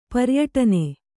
♪ paryaṭane